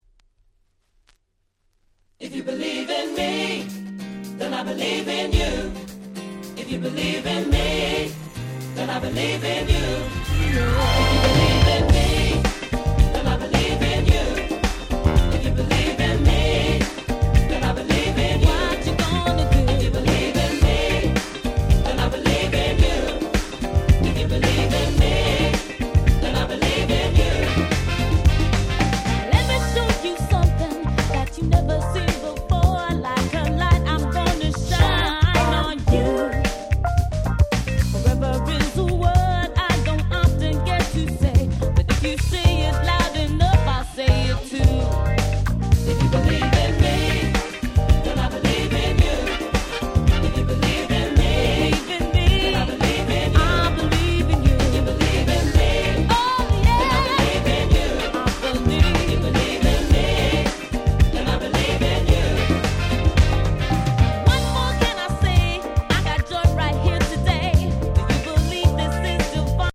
95' Nice Acid Jazz !!